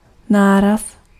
Ääntäminen
US : IPA : /sɝdʒ/